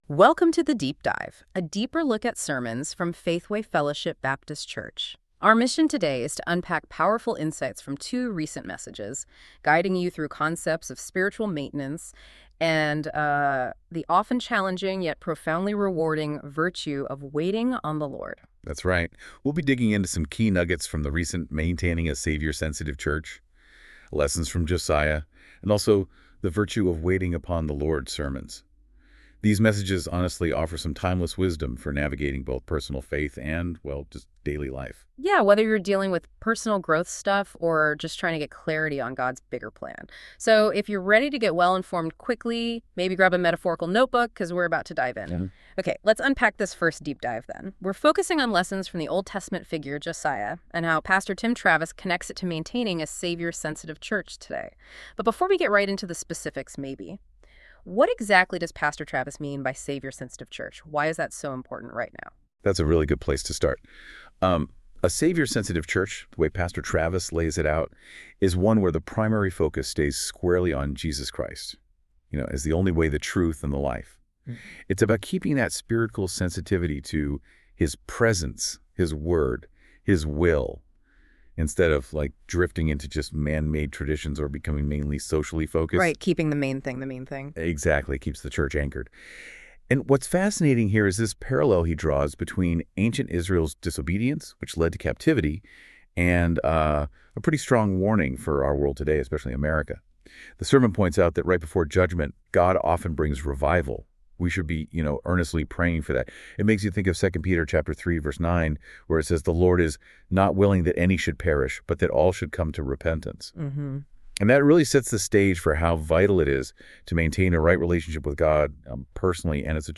This content is AI generated for fun.